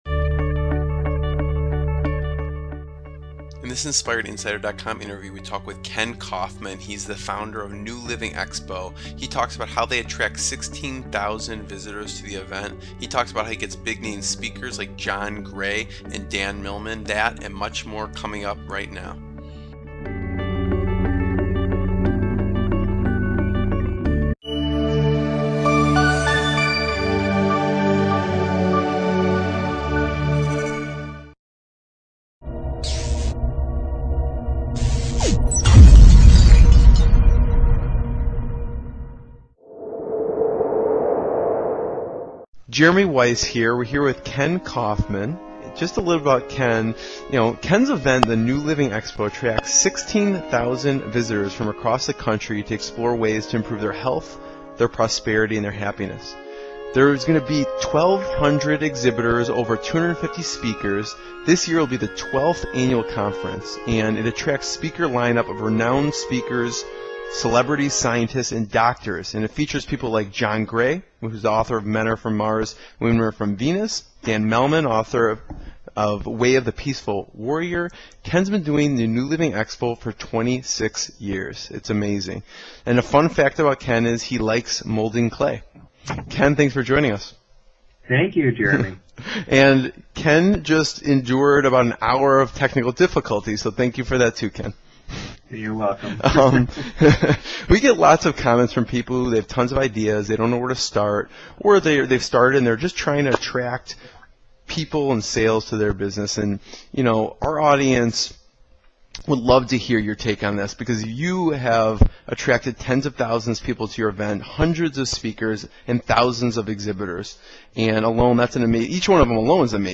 INspired INsider - Inspirational Business Interviews with Successful Entrepreneurs and Founders